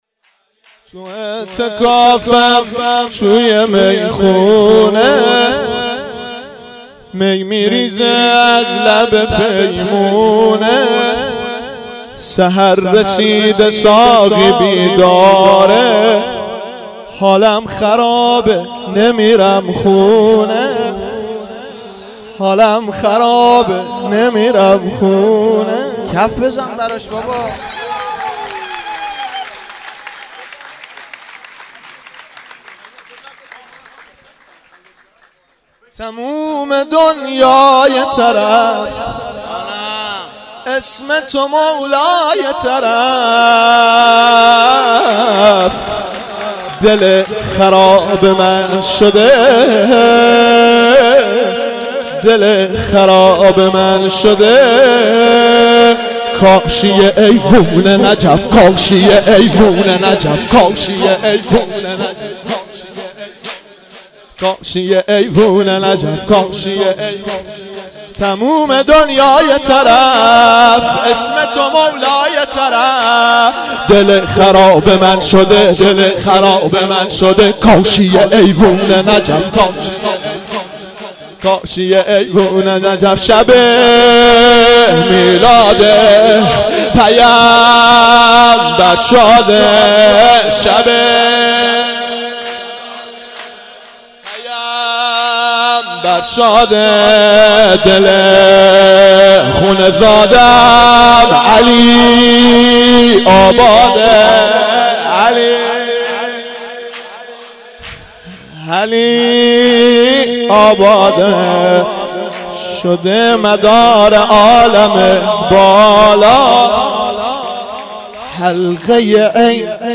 سرود دوم